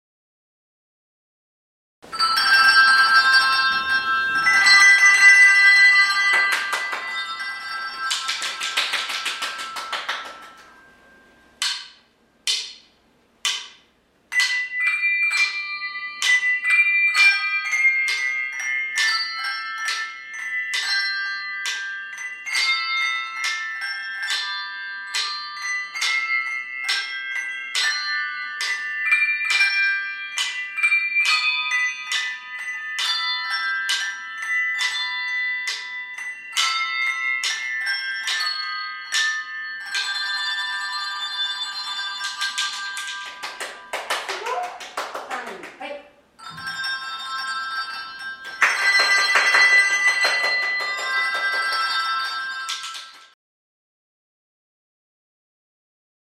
２年生ハンドベル
６年生との交流の時間に披露したハンドベルの演奏です。 先日、教室で発表会を行いました。 大きな声で歌が歌えない中で、ハンドベルで、きれいなハーモニーを奏でました。
２年生ハンドベル.mp3